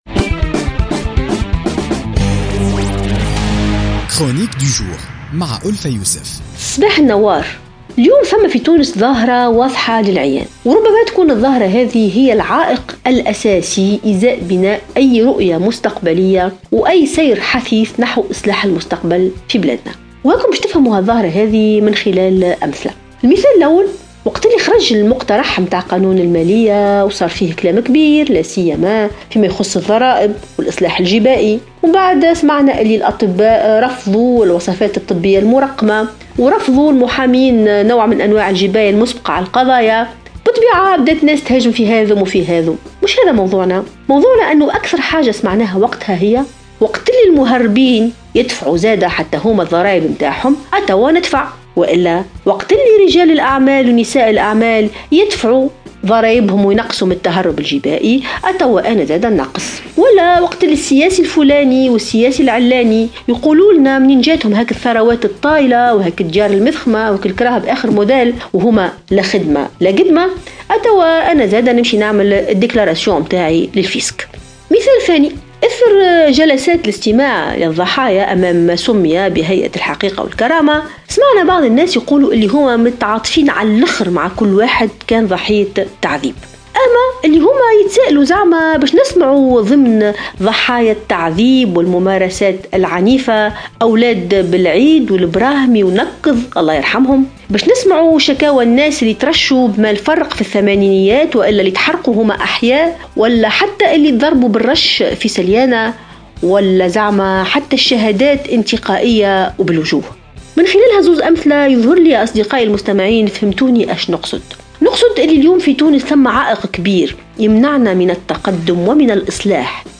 تطرقت الكاتبة ألفة يوسف في افتتاحية اليوم الثلاثاء 6 ديسمبر 2016 إلى ظاهرة طغت لتصبح عائقا أمام بناء أي رؤية مستقبلية أو سعي حثيث نحو اصلاح المستقبل في بلادنا .